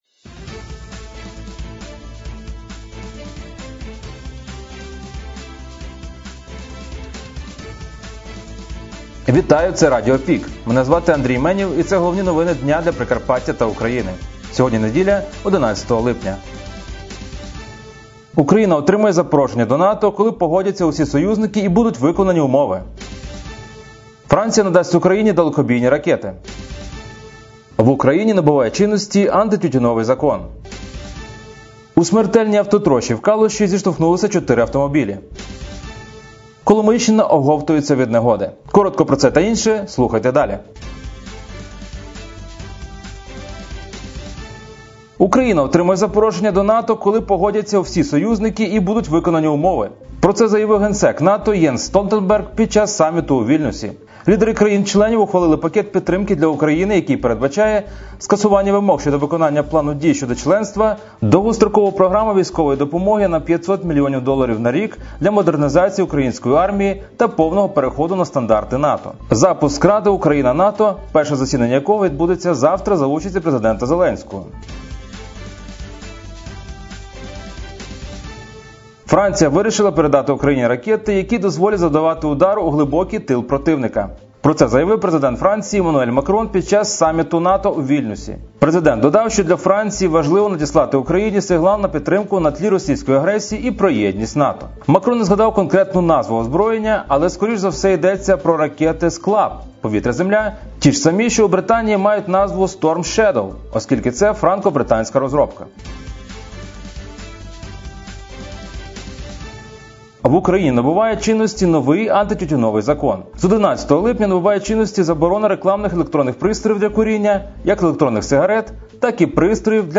Пропонуємо вам актуальне за день - у радіоформаті.
Радіо ПІК: головні новини України та Прикарпаття